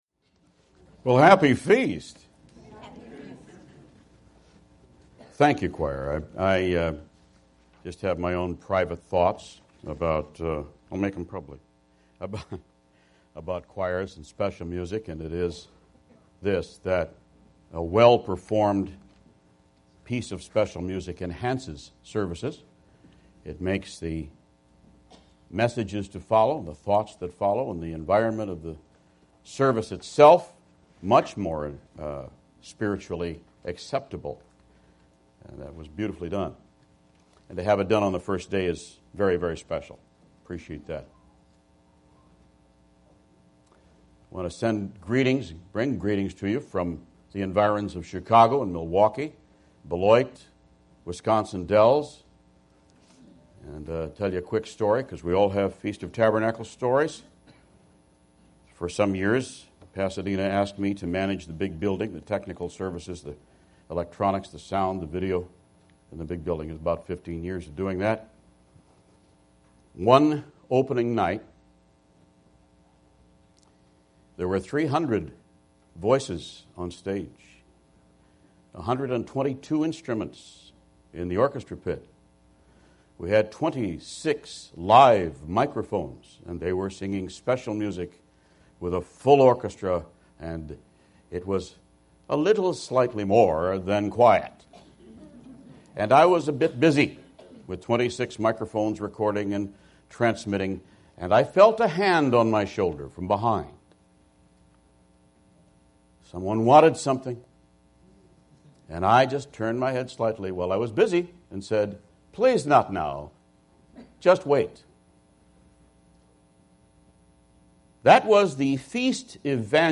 This sermon was given at the Ocean City, Maryland 2015 Feast site.